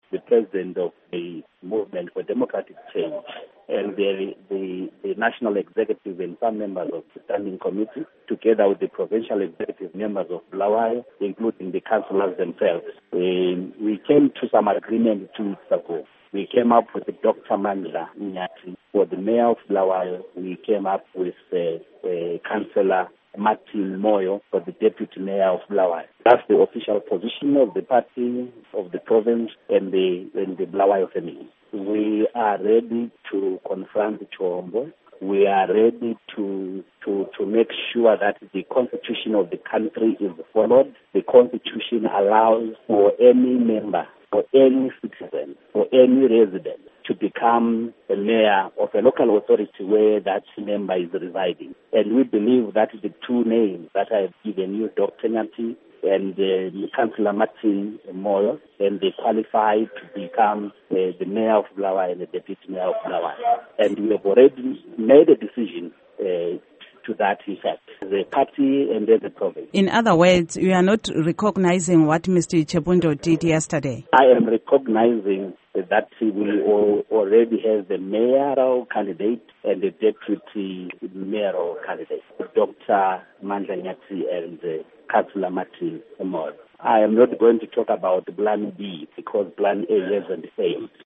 Ingxoxo LoMnu. Gorden Moyo